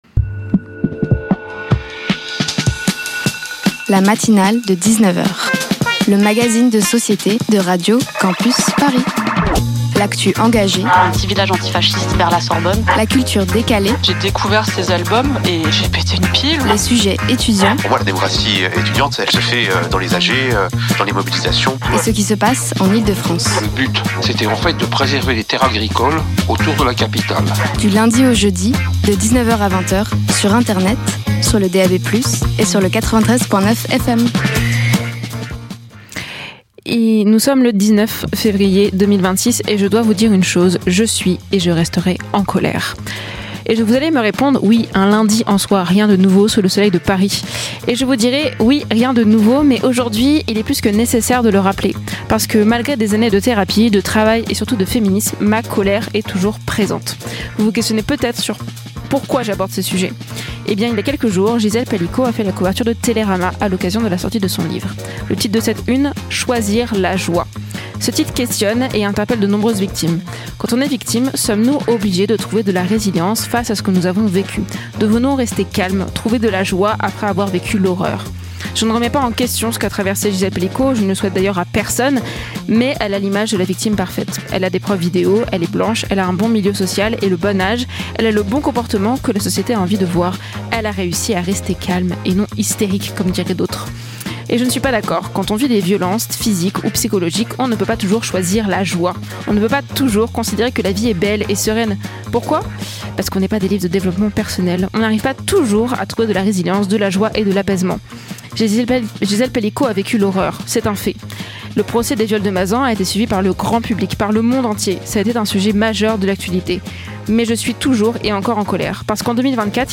L'écologie sans attendre l’État & le métier de maquilleur effets spéciaux Partager Type Magazine Société Culture jeudi 19 février 2026 Lire Pause Télécharger Ce soir